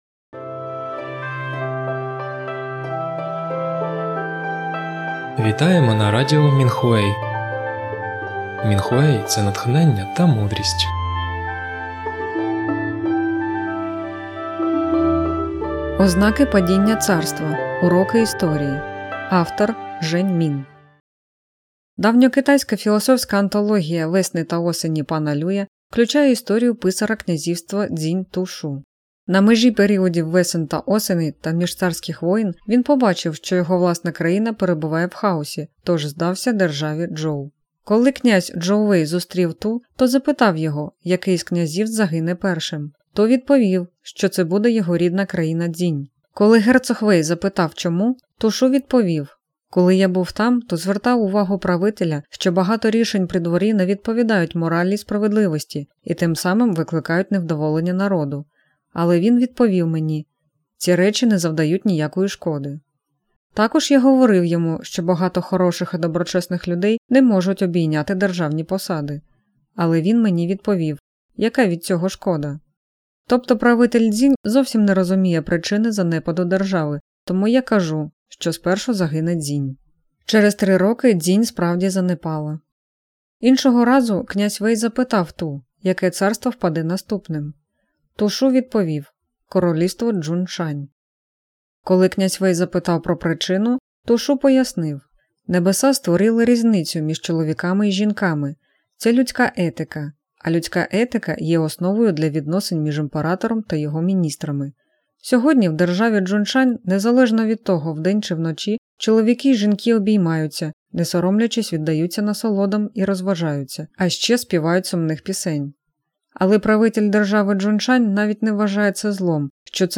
Музику з подкастів написали й виконали практикувальники Фалунь Дафа.